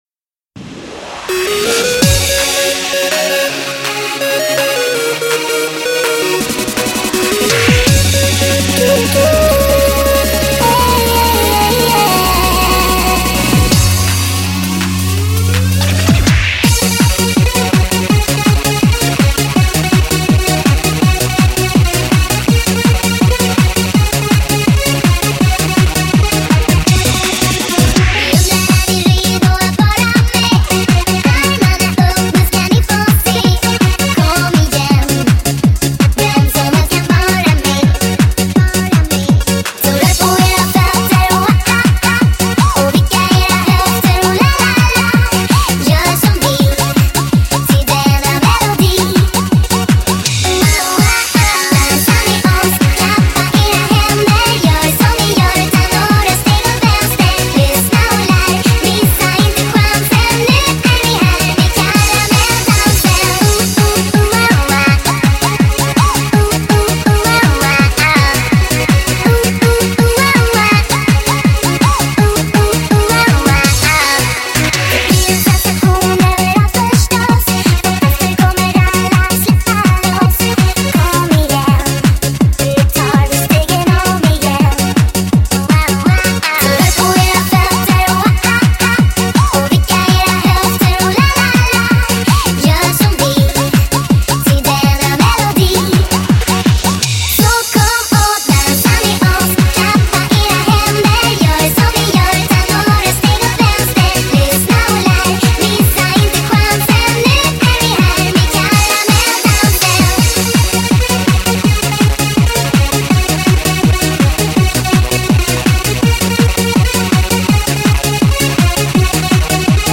Просто веселая песенка для подъема настроения ^^